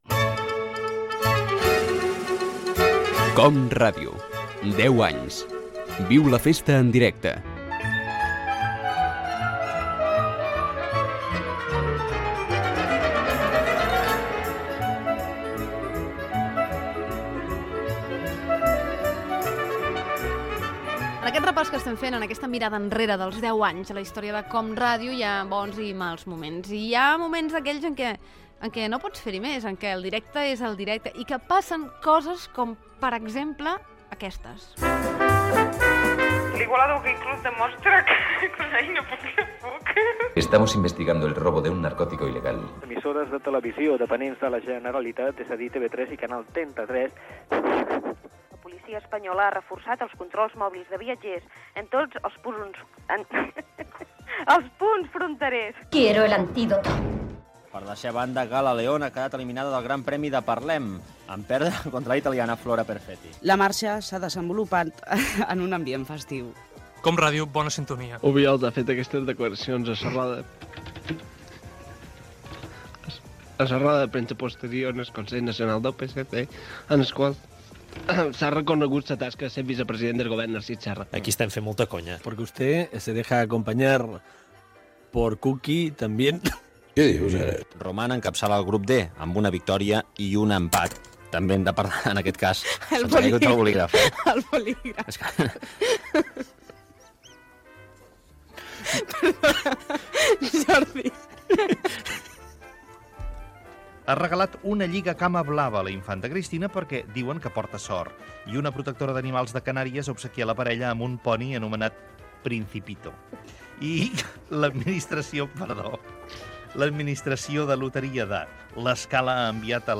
Entreteniment
Fragment extret de l'arxiu sonor de COM Ràdio